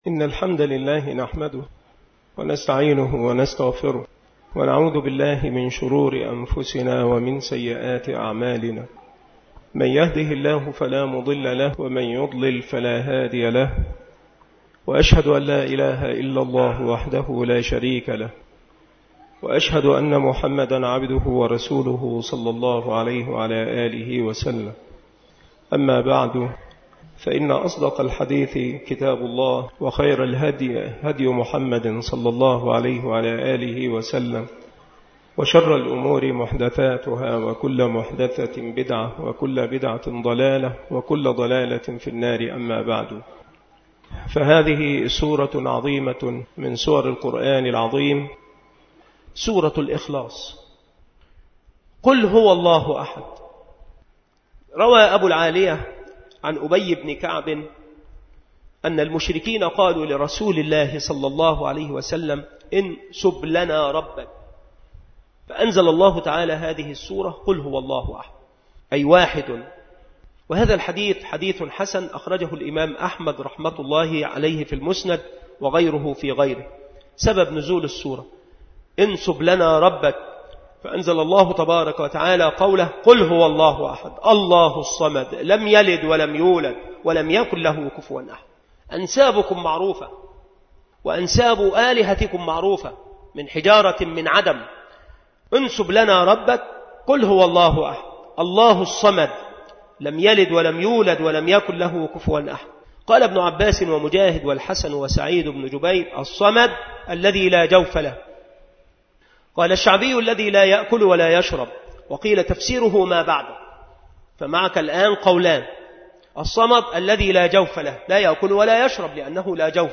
مكان إلقاء هذه المحاضرة بالمسجد الشرقي بسبك الأحد - أشمون - محافظة المنوفية - مصر